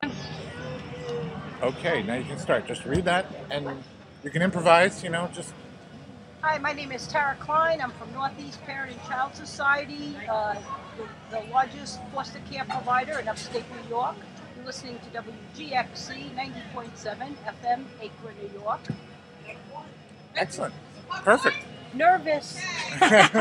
at Athens Street Festival